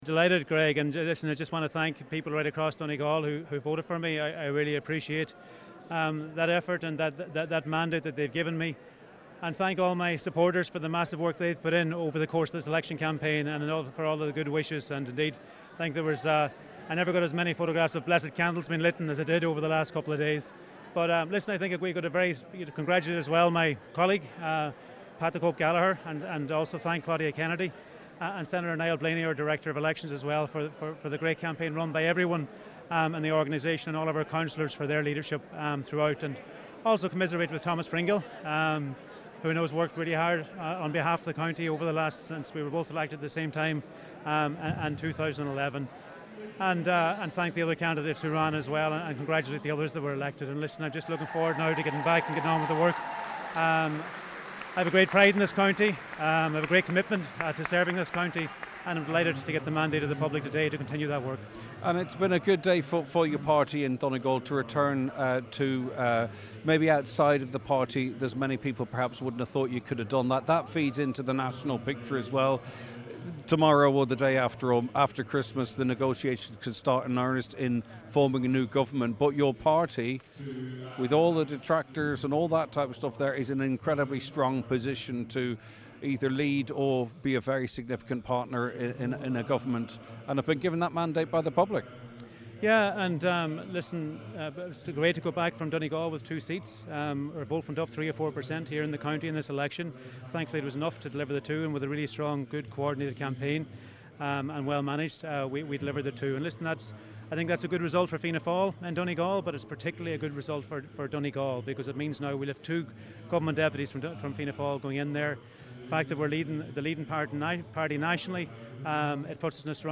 Minister McConalogue says he looks forward to continuing his commitment to serve Donegal: